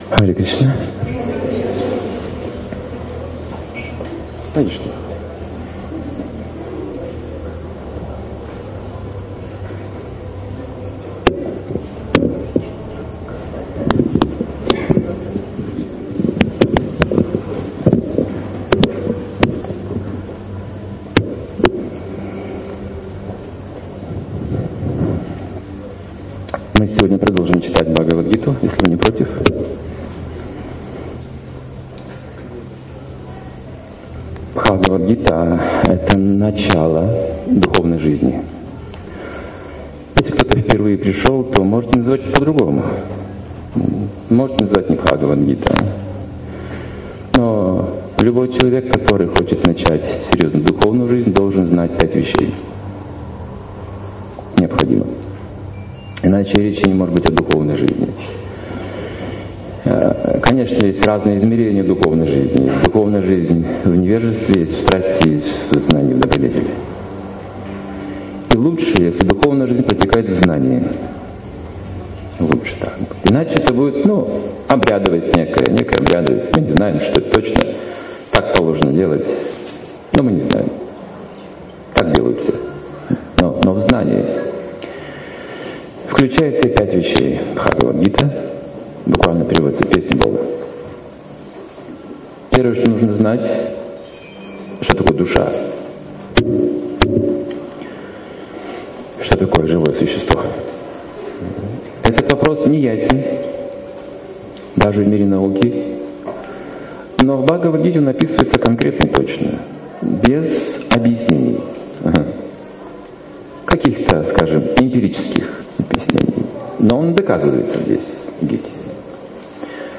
Темы, затронутые в лекции: Бхагавад-гита - начало духовной жизни. Различные измерения духовной жизни.